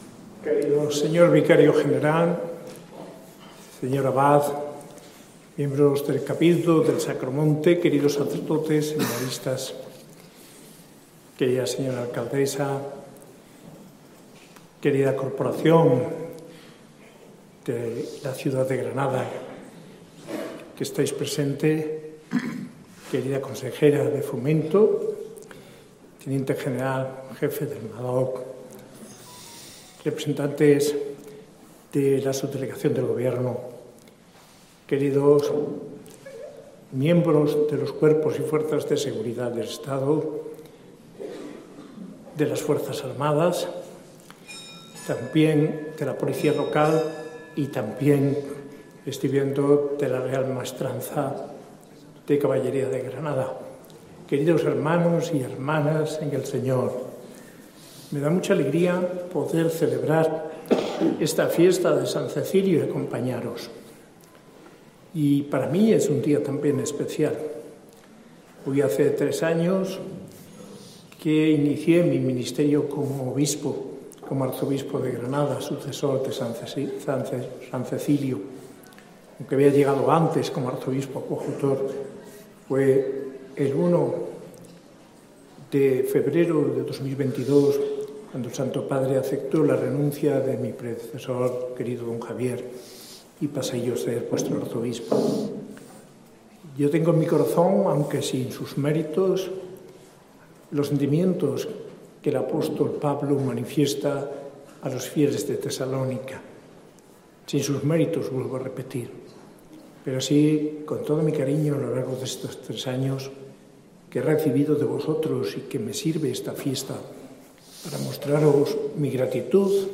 Homilía del arzobispo de Granada, Mons. José María Gil Tamayo, en la Eucaristía de la Solemnidad de san Cecilio, patrón de Granada, coincidienco con la celebración litúrgica del Voto de la ciudad de Granada, celebrada el 1 de febrero de 2026 en la Abadía del Sacromonte.